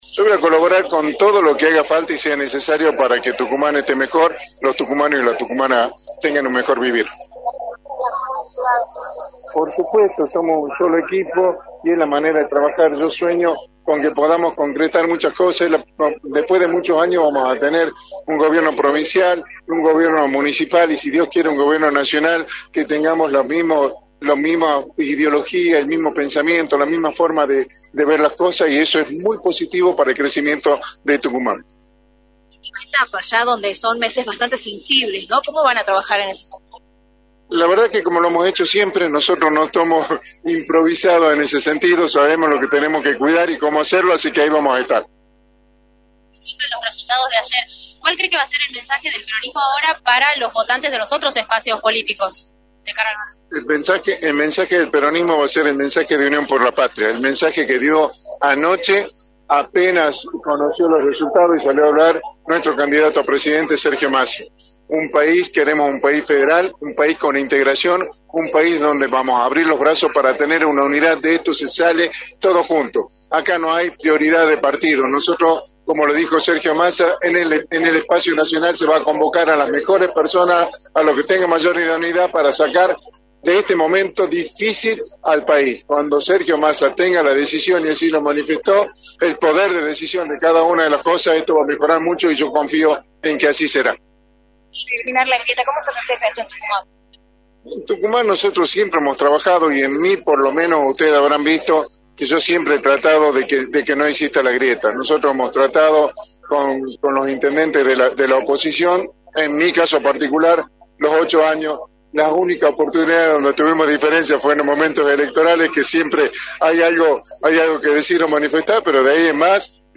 Miguel Acevedo, Ministro del Interior y Vicegobernador electo, analizó en Radio del Plata Tucumán, por la 93.9, el resultado de las elecciones generales y remarcó cuáles son sus expectativas para noviembre.